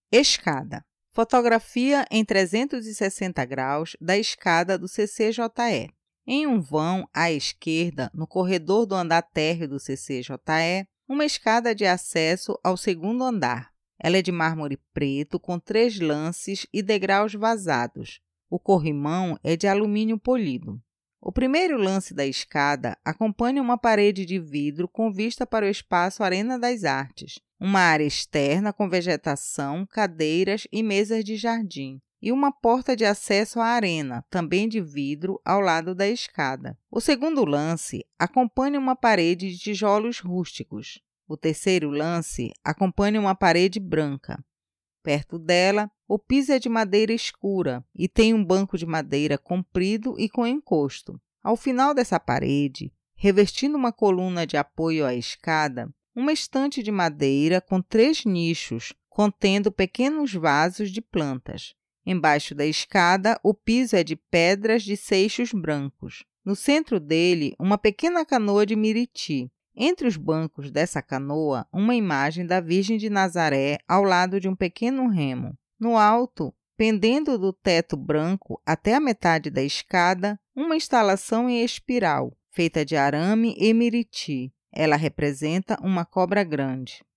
Escada audiodescrição